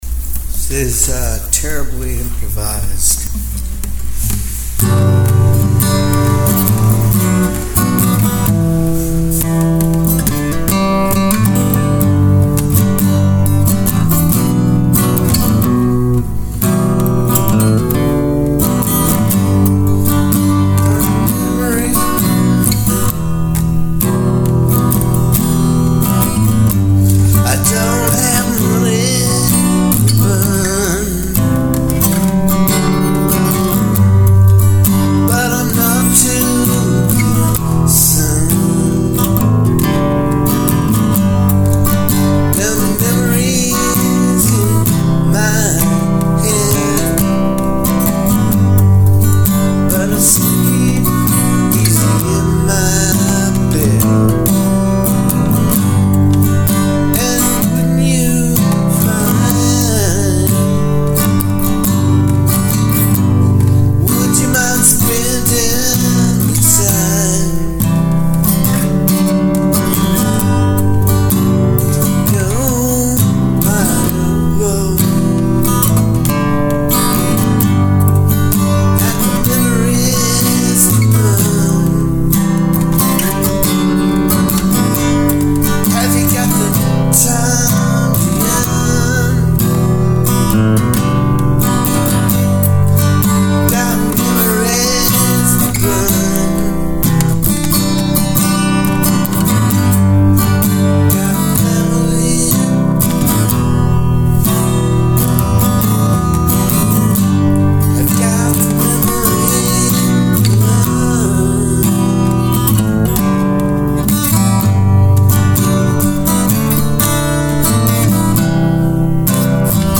(this is terribly improvised)